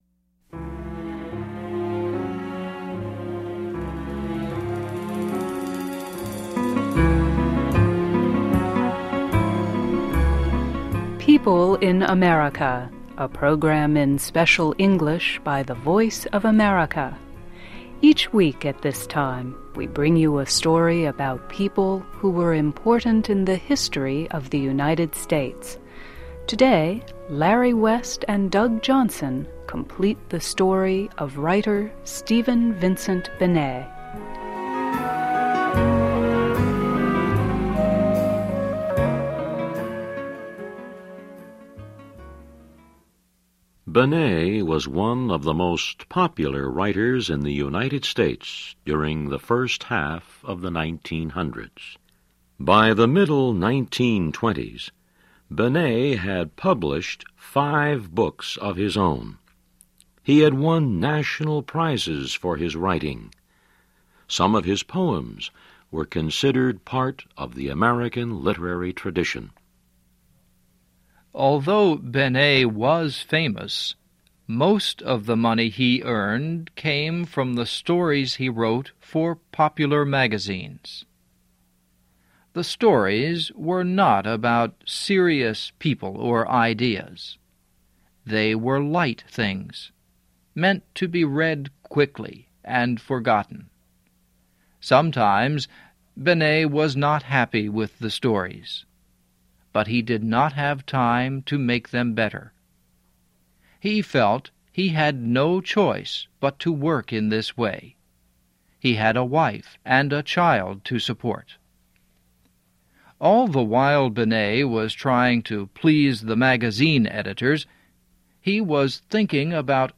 PEOPLE IN AMERICA -- a program in Special English by the voice of America. Each week at this time, we bring you a story about people who were important in the history of the United States.